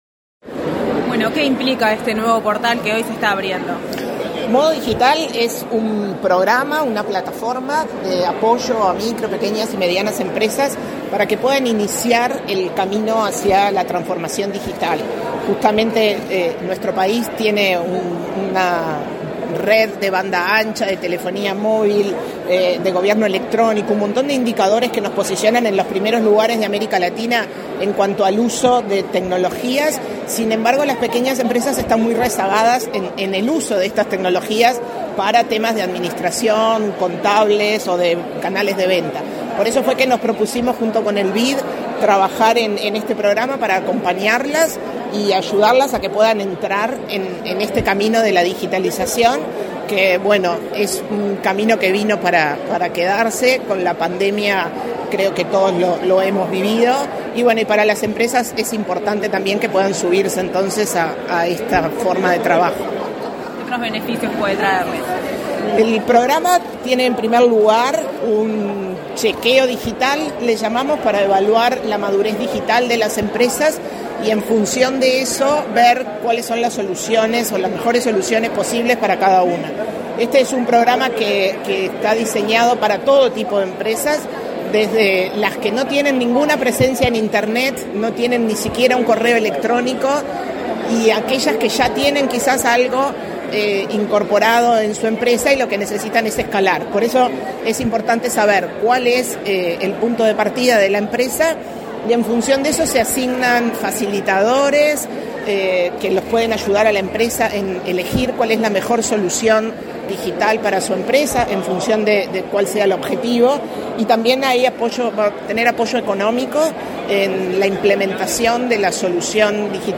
Declaraciones de la presidenta de ANDE, Carmen Sánchez